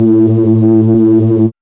2508R BASS.wav